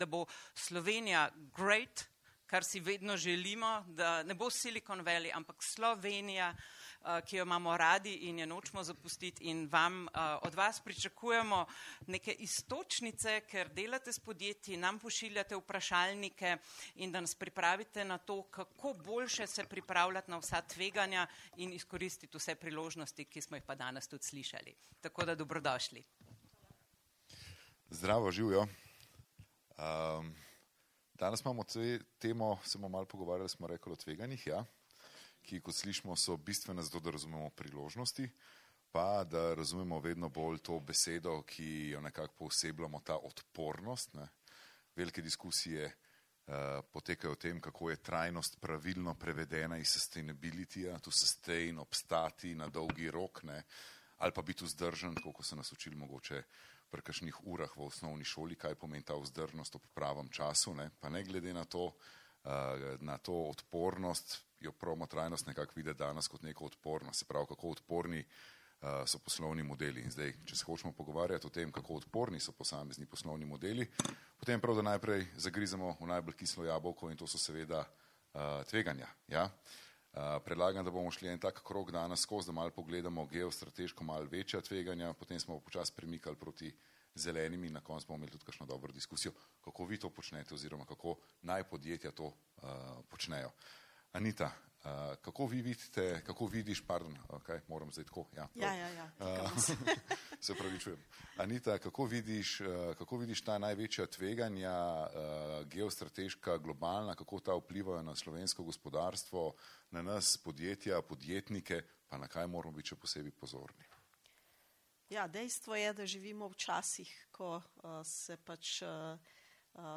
posnetek_15-5-_okrogla_miza.mp3